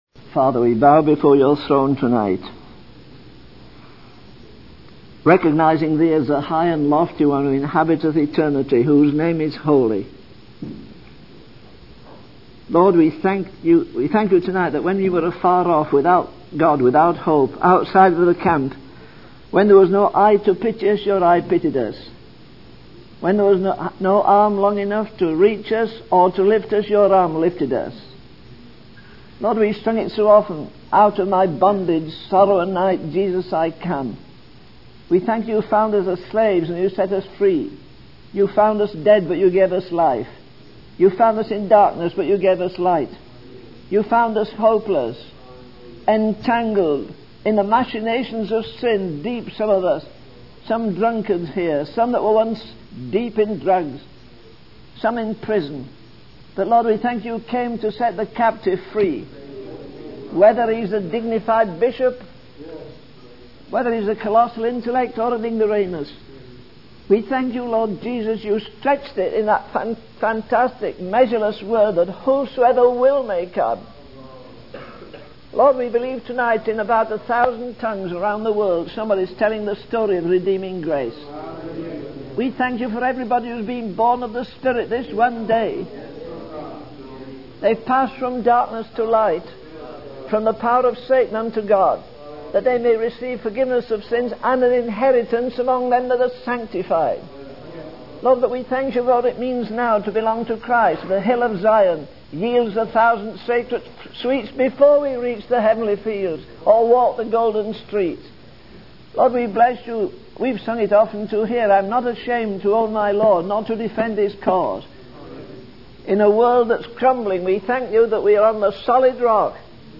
In this sermon, the preacher emphasizes the need for revival in communities and governments. He mentions that two revivals can change the moral climate of a community.